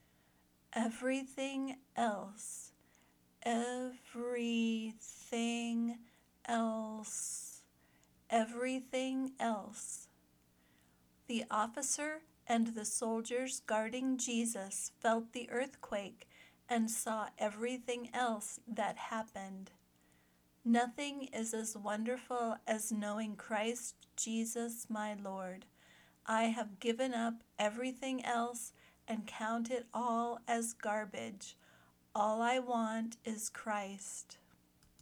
/ˈev ri θɪŋ els/ (noun phrase, singular)